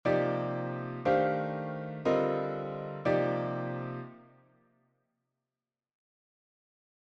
Improvisation Piano Jazz
Construction d’accords avec des quartes